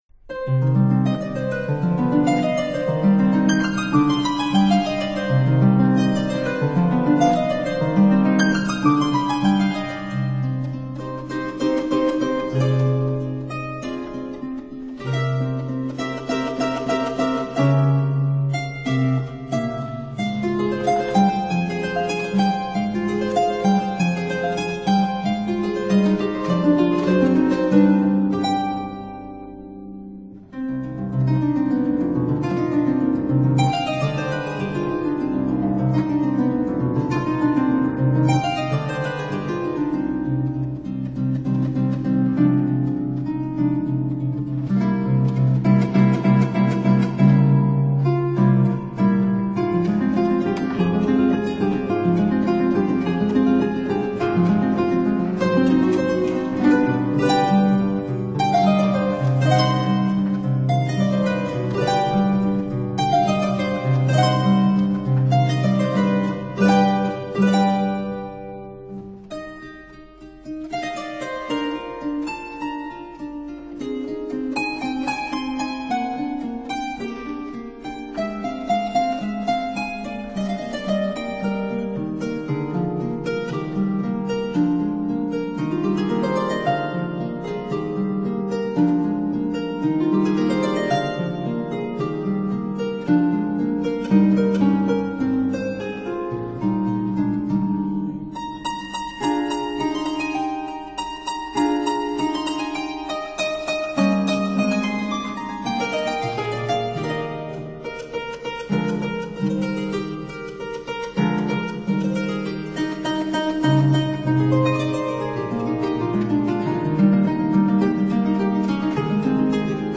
Single Action Harp (Period Instruments)